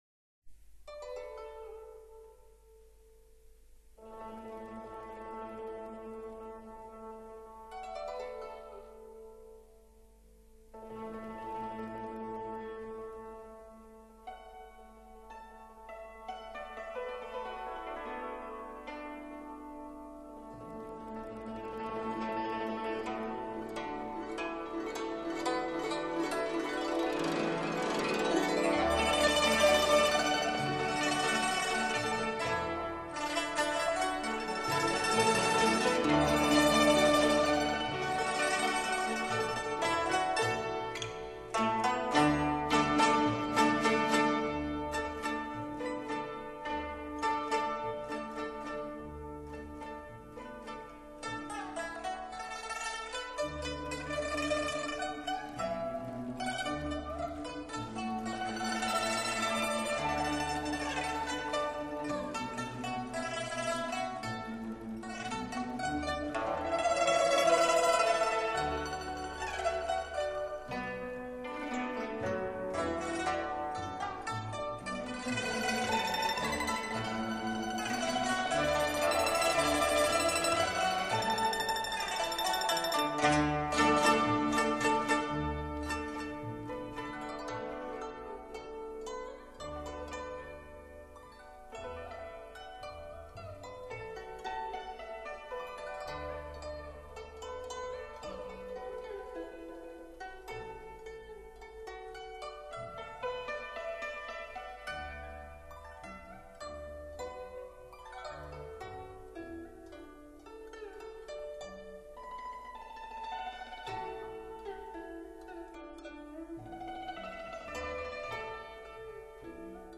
[18/1/2010]民乐合奏《秋月》 激动社区，陪你一起慢慢变老！